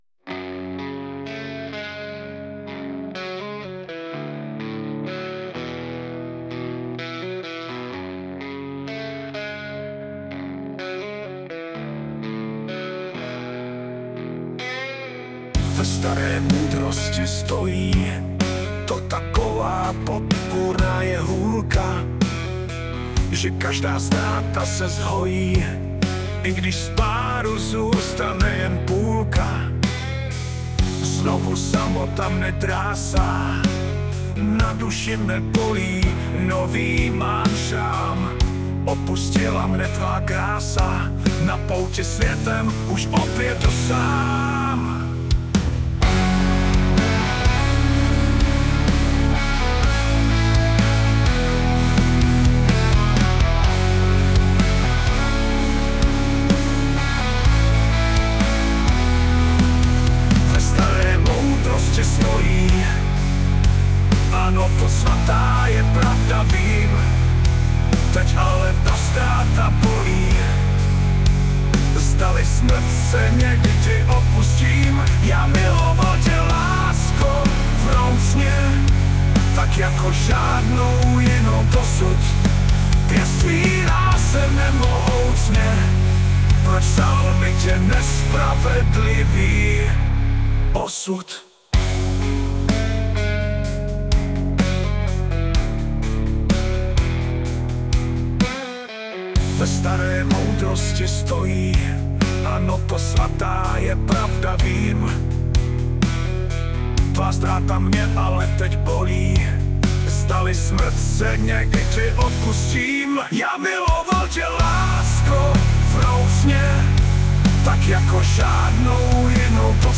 Smutné
* hudba, zpěv: AI